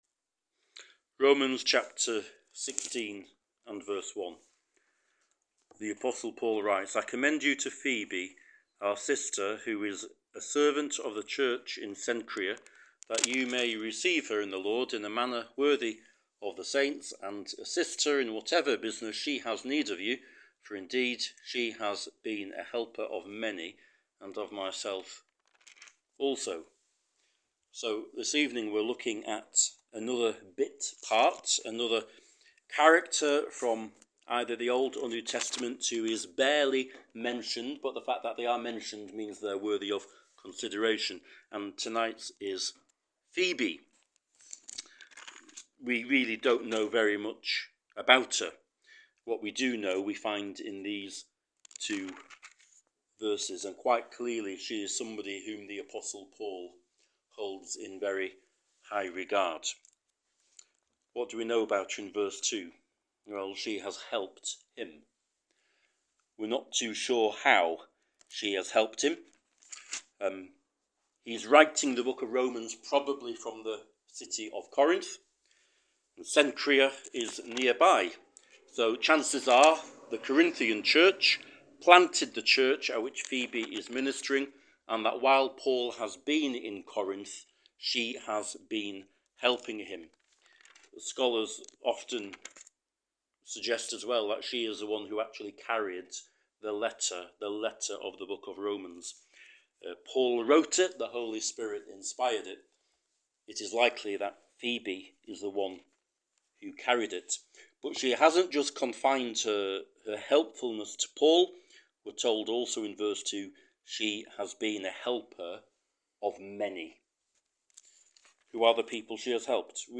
Sermon Podcasts Downloads | Salem Chapel, Martin Top